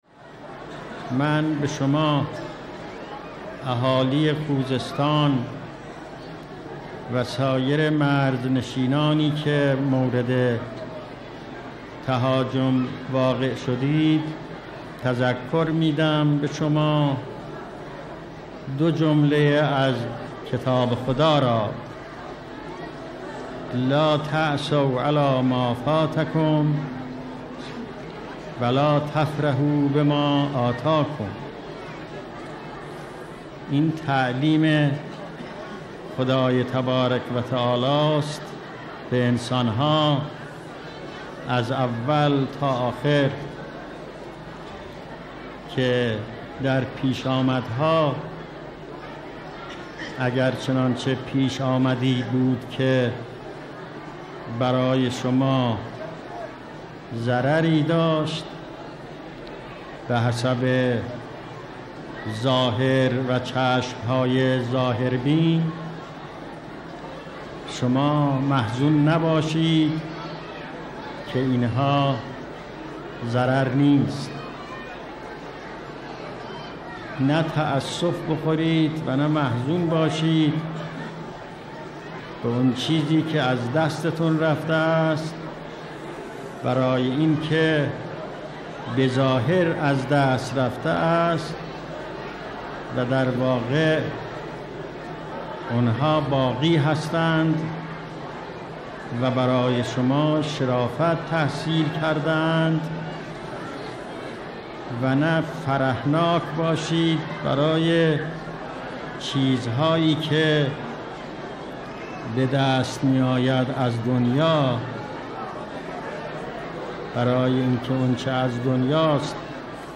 تجلیل از رشادتها و فداکاریهای مردم خوزستان و مناطق جنگی ؛ برشی از سخنان امام خمینی (ره) در دیدار با خانواده‌های شهدا و معلولان جنگ تحمیلی خوزستان ( 13 فروردین 1360)